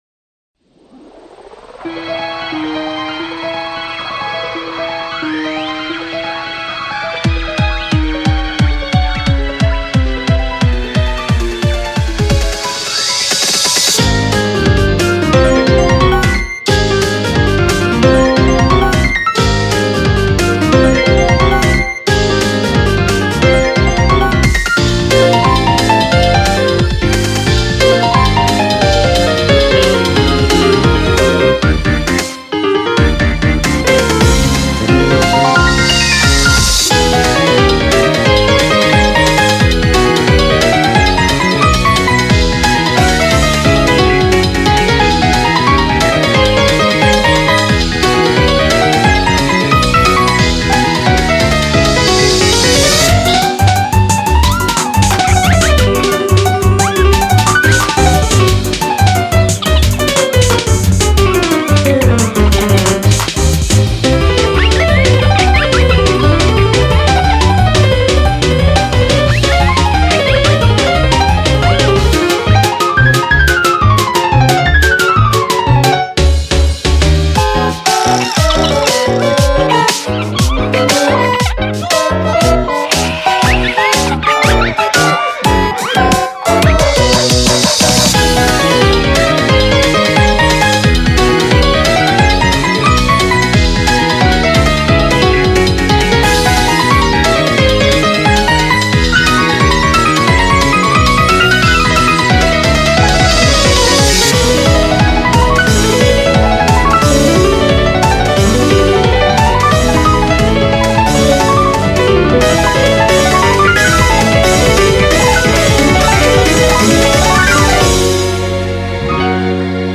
BPM89-178